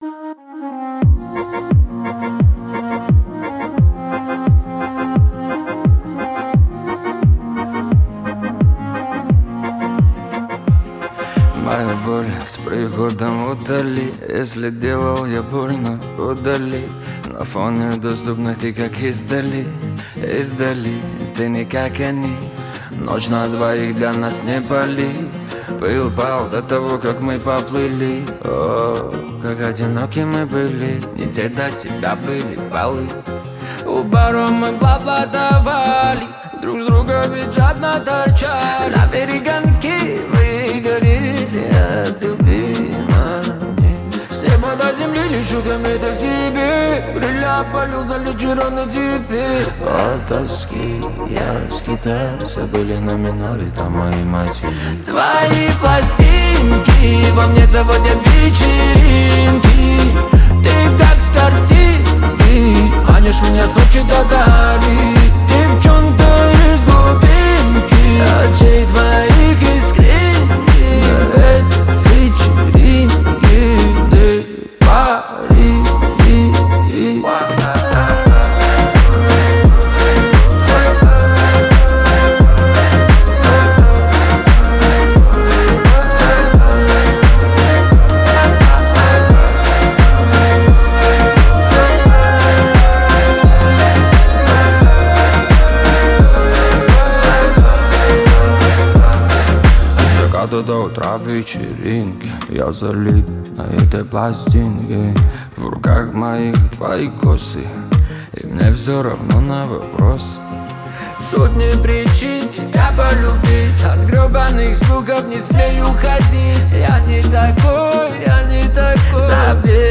Танцевальная музыка
весёлые песни
танцевальные песни